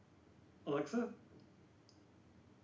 wakeword
alexa-02.wav